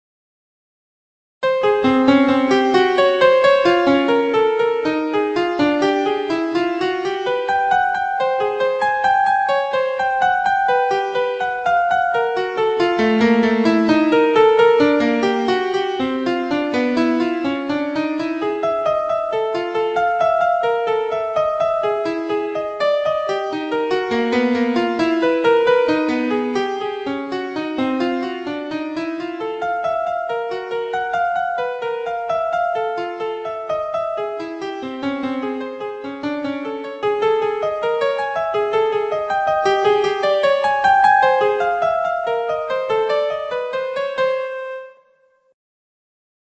(The timing and dynamics are composed by the program and played by a MIDI player.)